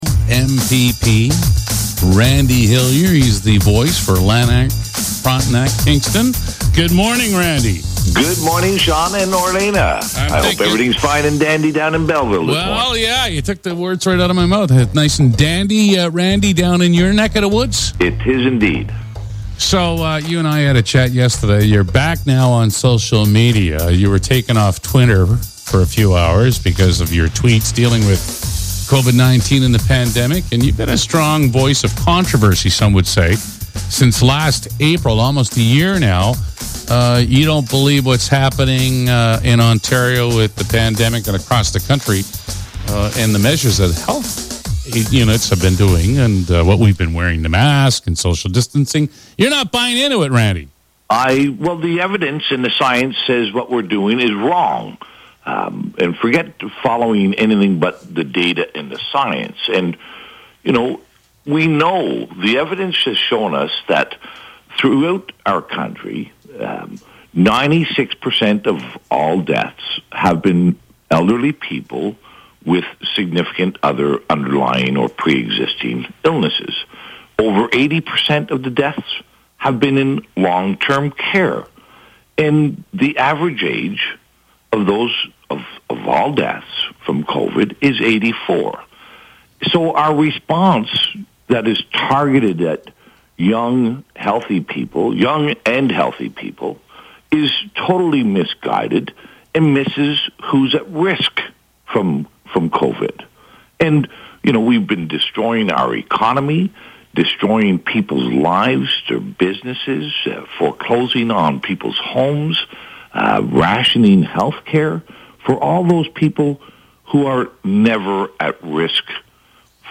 A regional Member of Provincial Parliament, who was recently removed from Twitter over COVID-19 messaging, talked with the Mix Morning Crew Thursday morning.
randy-hillier-full-interview-1.mp3